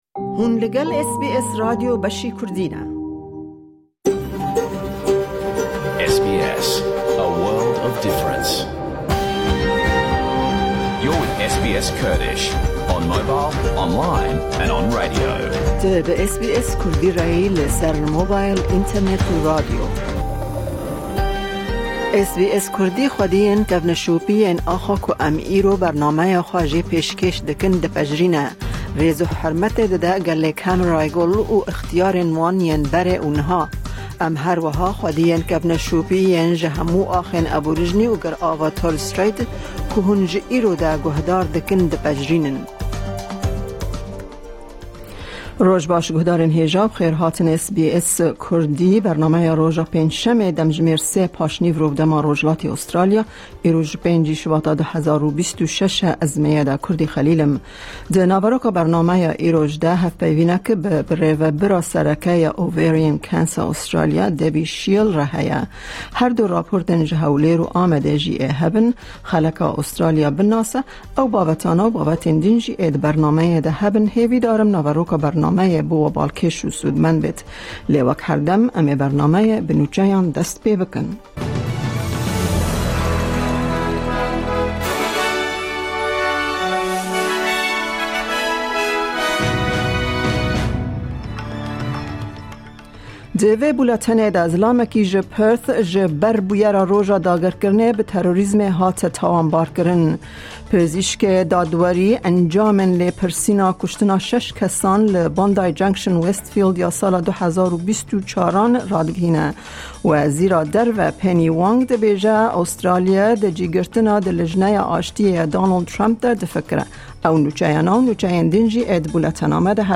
Nûçe, raportên ji Hewlêr û Amedê, hevpeyvîn û gelek babetên di yên cur bi cur di naveroka bernameyê de tên dîtin.